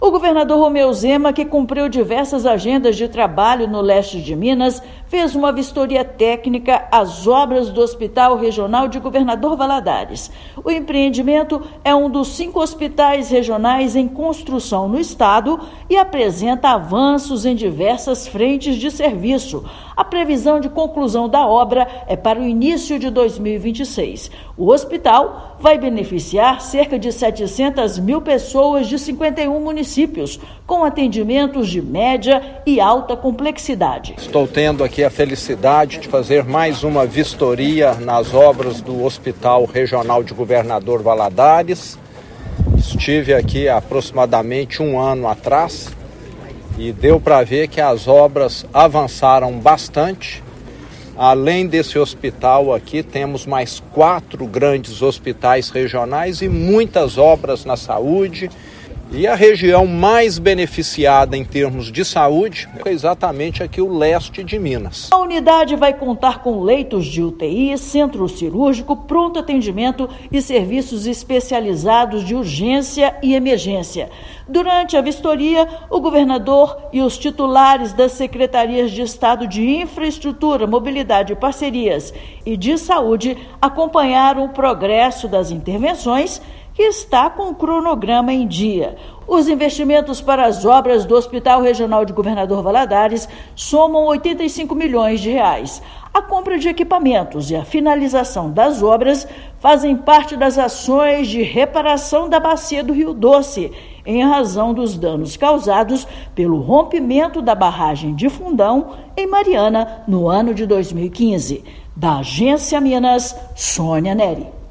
Com previsão de conclusão para o próximo ano, empreendimento vai beneficiar cerca de 700 mil pessoas em 51 municípios das regiões Leste e do Vale do Rio Doce. Ouça matéria de rádio.